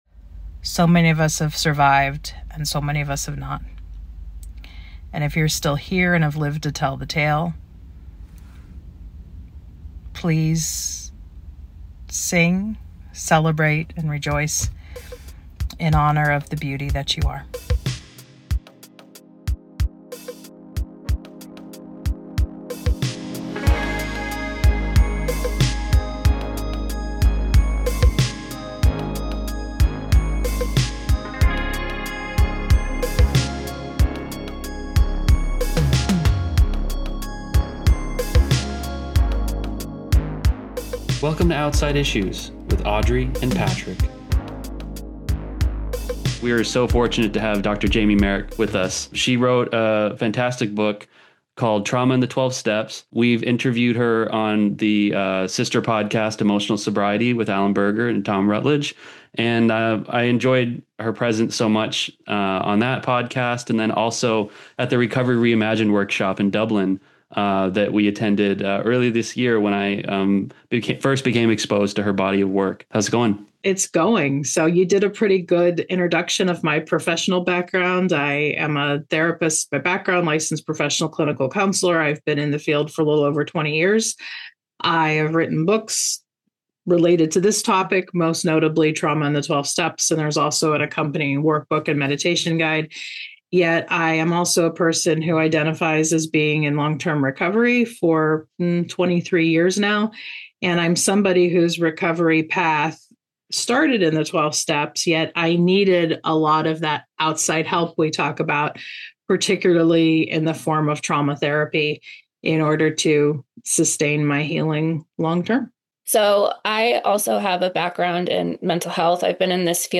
Main show theme